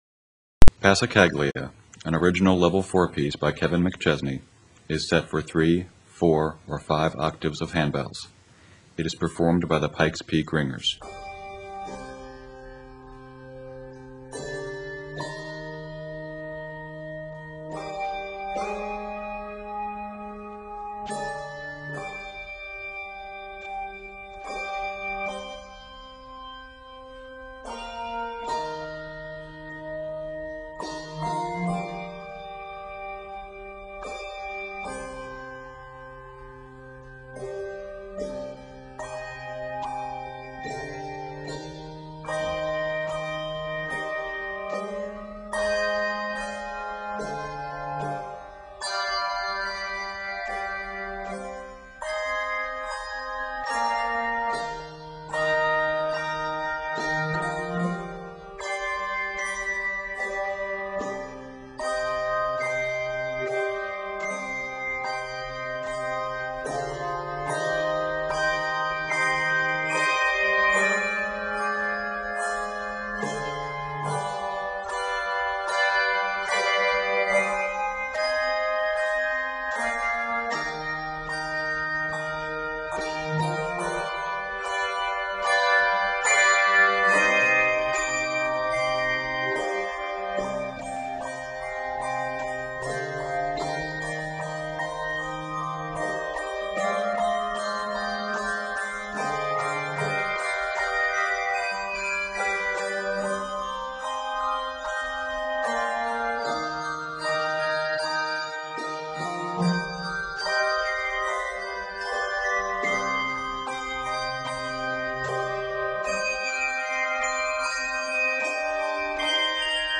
handbell choir